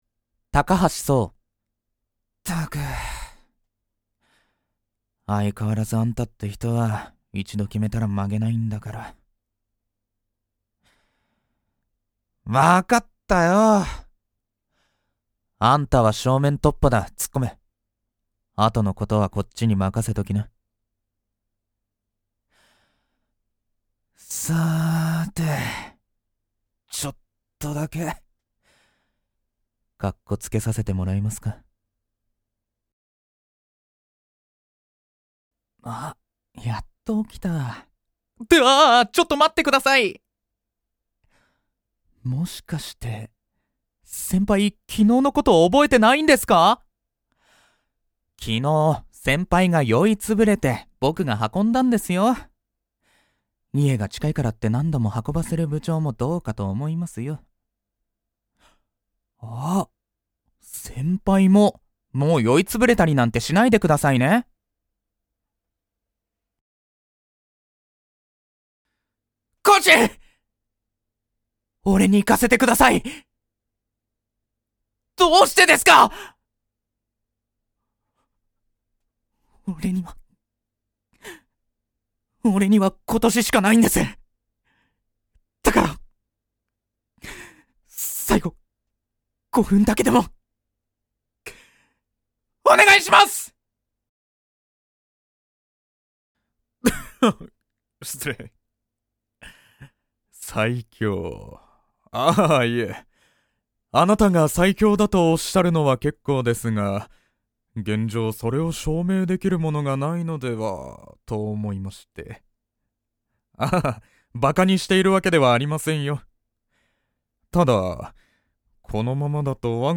◆台詞